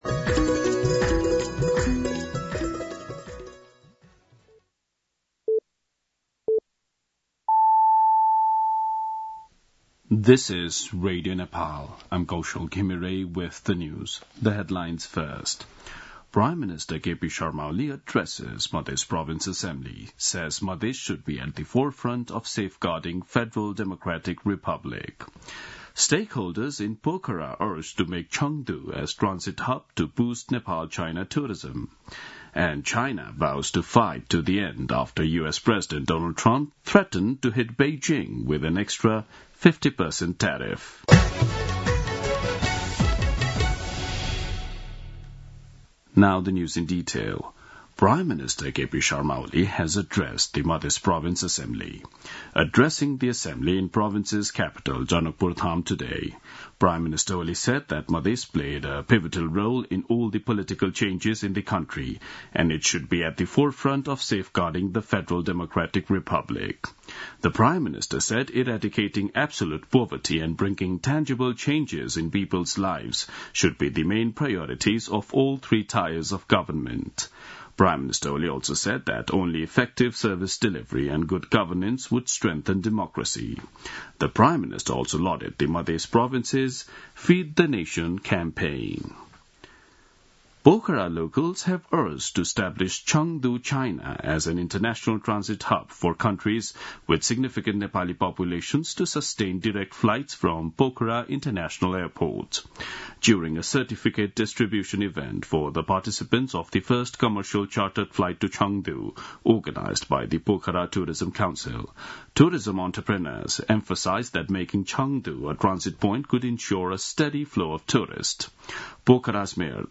दिउँसो २ बजेको अङ्ग्रेजी समाचार : २६ चैत , २०८१
2-pm-English-News-12-26.mp3